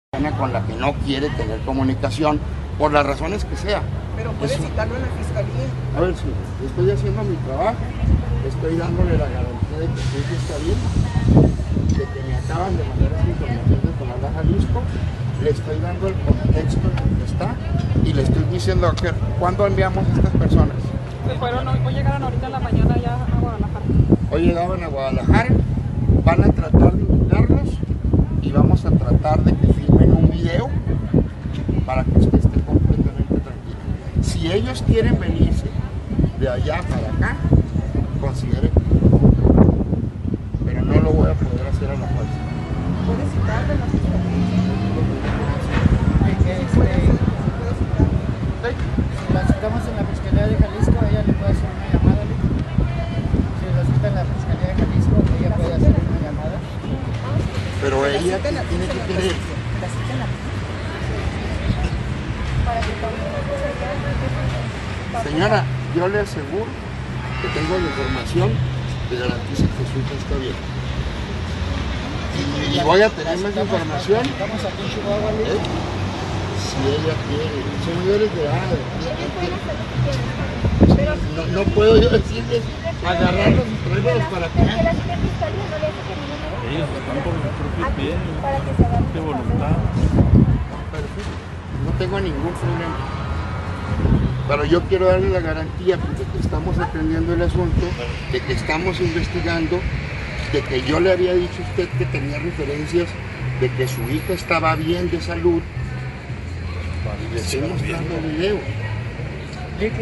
AUDIO: CÉSAR JAÚREGUI MORENO, FISCAL GENERAL DEL ESTADO (FGE)
Posterior a mostrar un vídeo a los padres, atendió a los medios de comunicación para confirmar los hechos que mostró a los familiares.